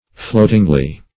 floatingly - definition of floatingly - synonyms, pronunciation, spelling from Free Dictionary Search Result for " floatingly" : The Collaborative International Dictionary of English v.0.48: Floatingly \Float"ing*ly\, adv. In a floating manner.